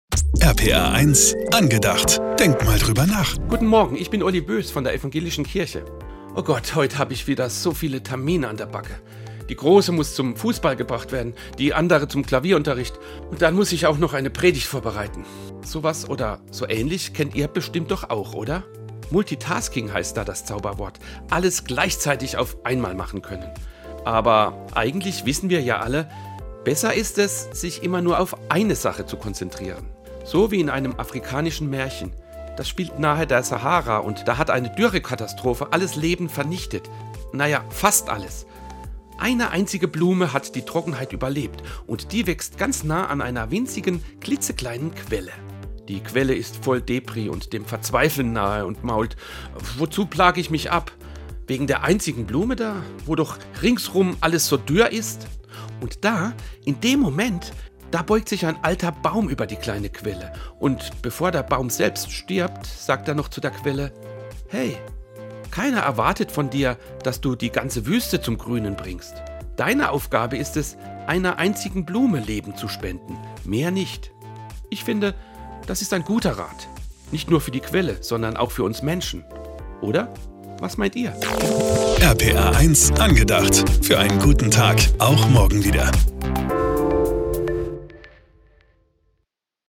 ProtCast Pfalz - Radioandachten aus Rheinland-Pfalz